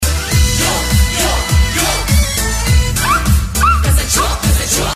Народные voice FX ищутся
Плз, кто работает с псевдо-народно-эстрадными аранжировками, подскажите, есть где нибудь народные FX, все эти девичьи визги, уханье разудалое, свист и проч., типа как в примере.